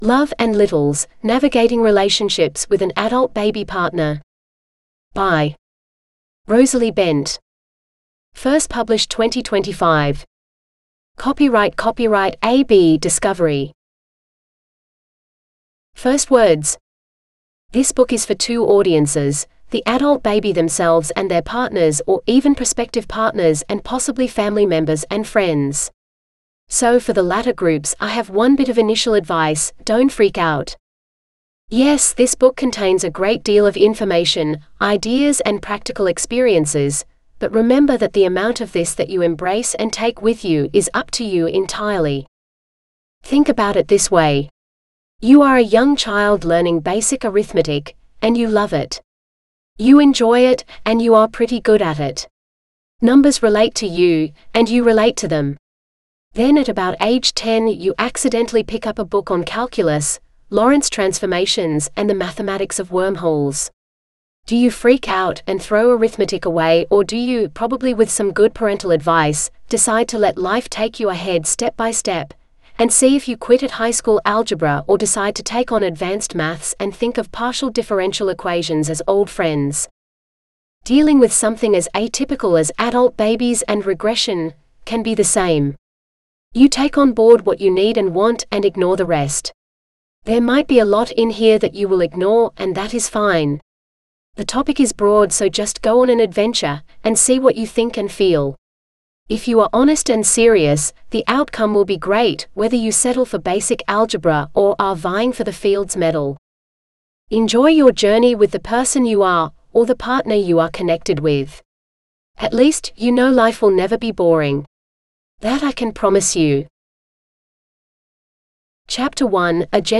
Love and Littles (AUDIOBOOK): $US6.50